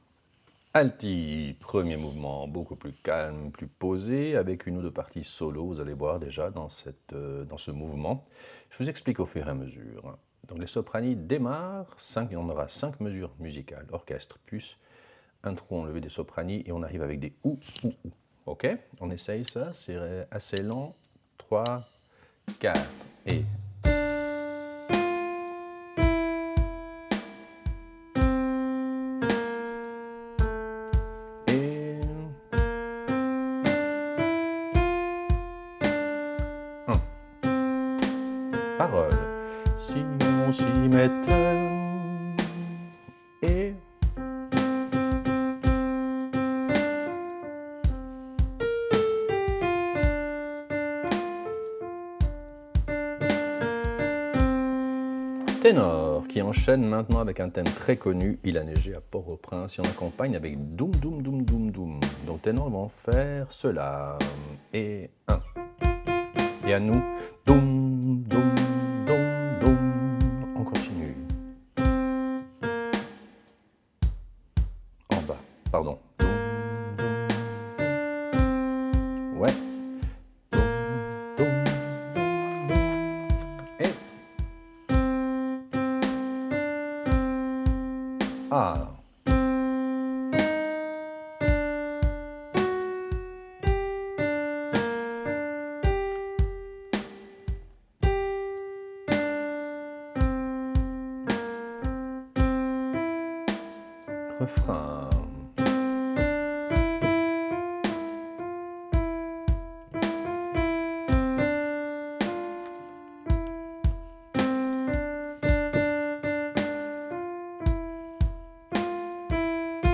Répétition SATB4 par voix
Soprano